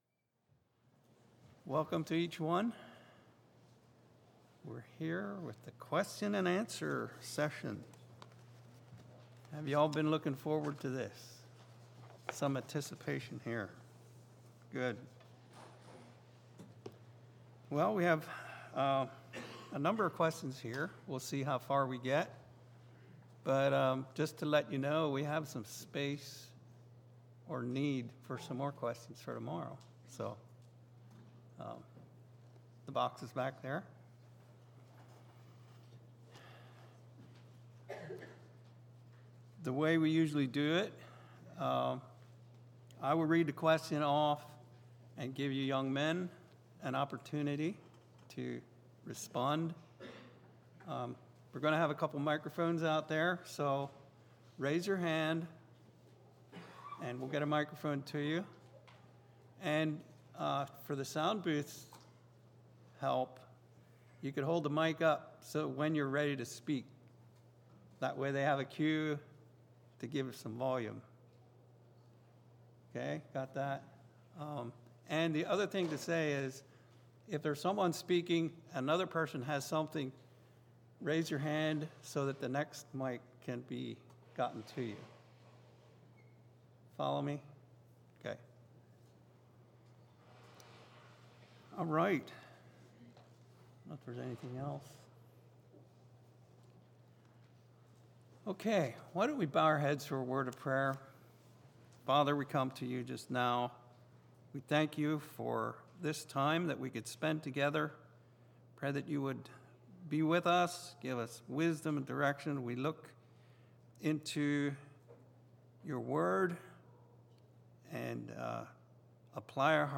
Q & A Session - Harmony Christian Fellowship
Series: Youth Bible School 2024